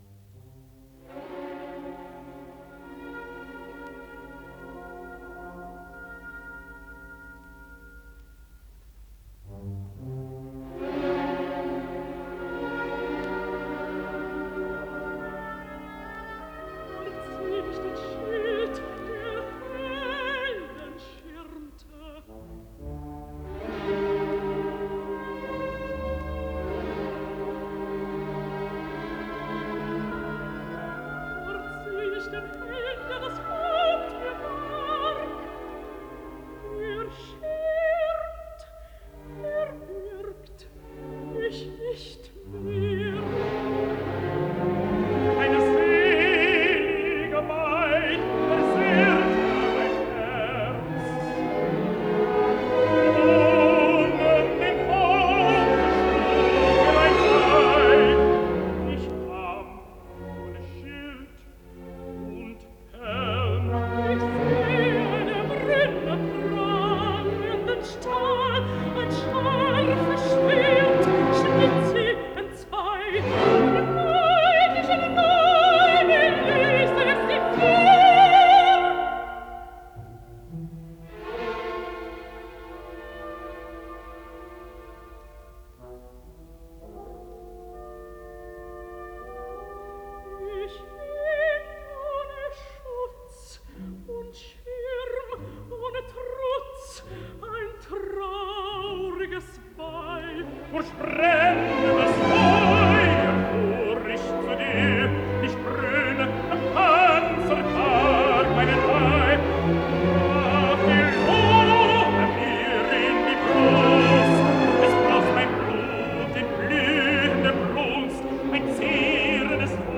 ПКС-07728 — Опера Зигфрид — Ретро-архив Аудио
Исполнитель: Солисты и оркестр Берлинской филармонии